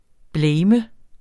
Udtale [ ˈblεjmə ]